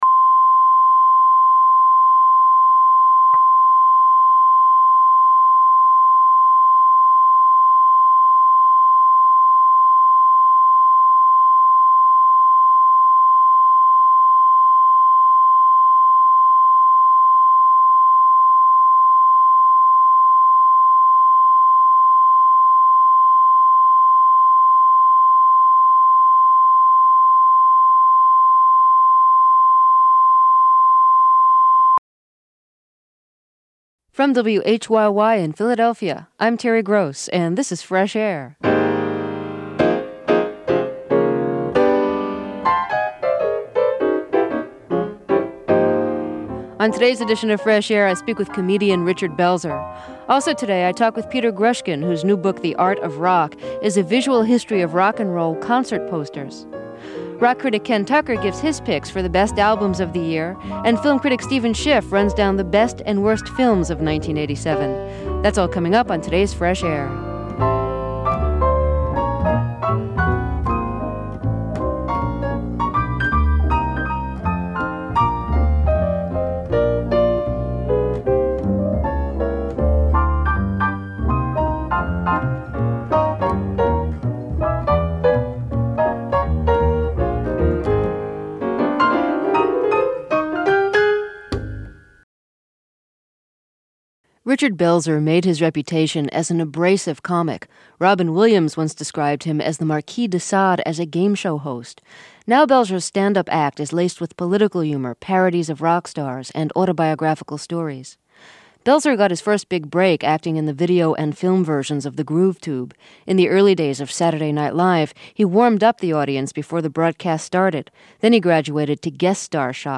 The sharp-witted standup comic, who died Feb. 19, got his start in comedy clubs in the 1970s, but was perhaps best known for playing Det. Munch on Law & Order: SVU. Originally broadcast in 1987.